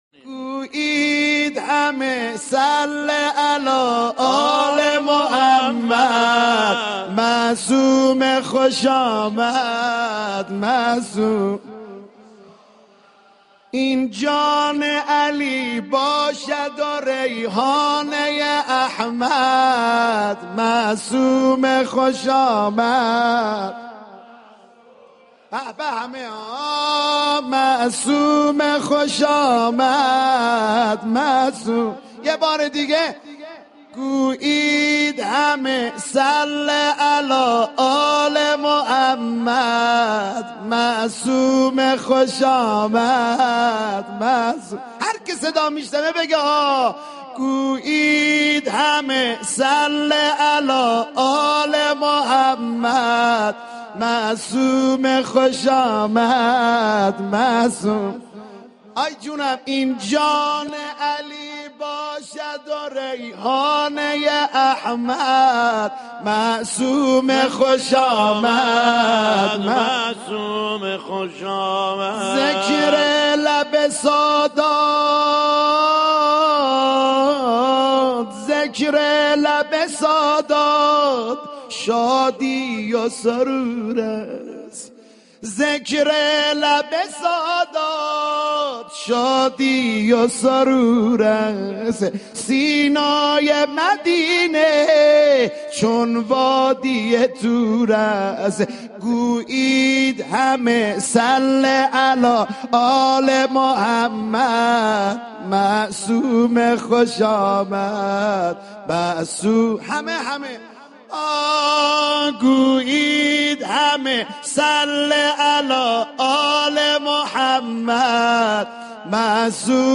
مولودی | معصومه خوش آمد
مدیحه خوانی